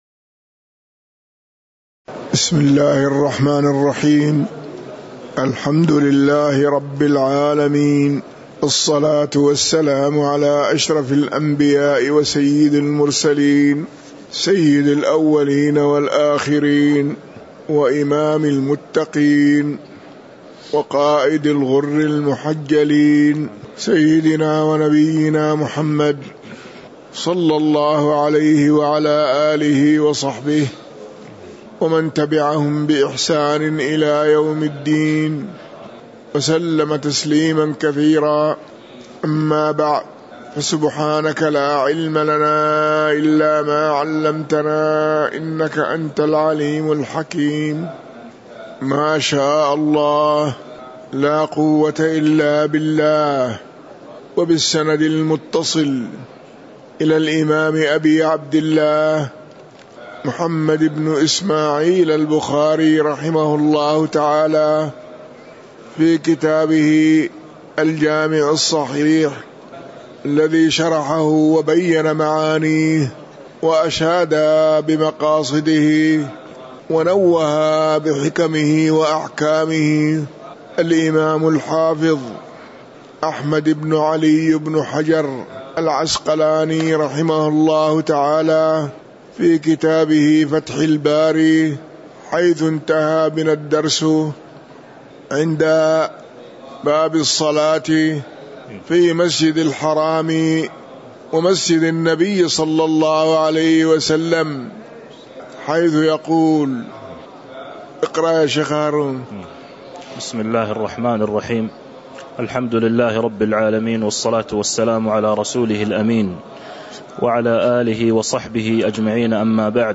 تاريخ النشر ٢٨ شوال ١٤٤٣ هـ المكان: المسجد النبوي الشيخ